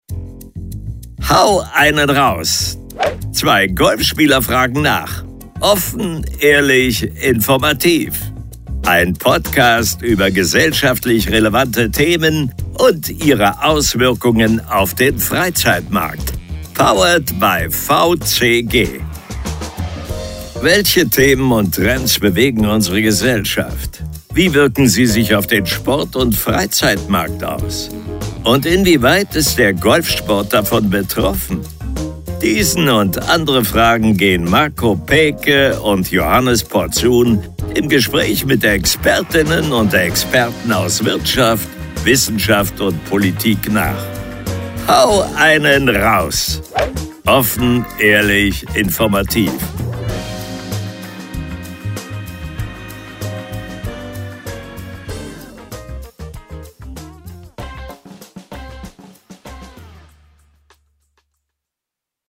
Podcast Intro – HÖRBEISPIELE mit SYNCHRONSPRECHER
Podcast Intro mit Profi Sprecher & Synchronsprecher & Synchronstimme - Produktion im Tonstudio!